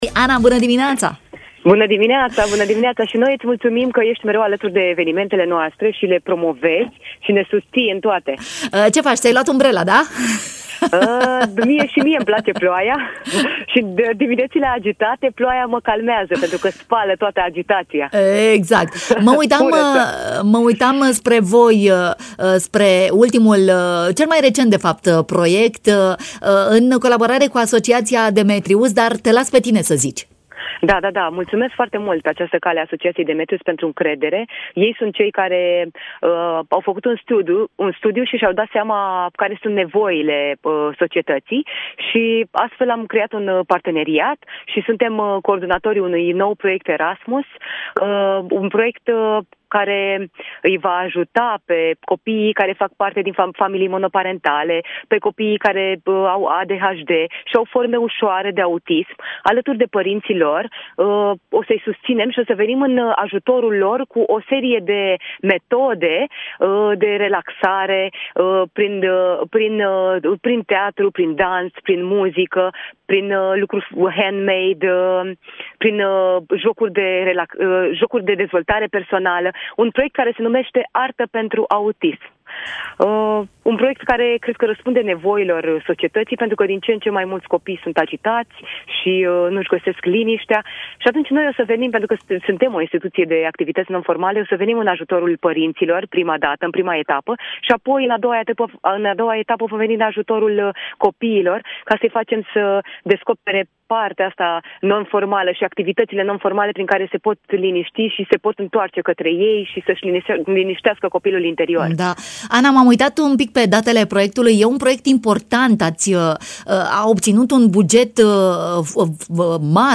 în matinal Radio Iași.